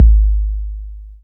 KIK 808 K 1.wav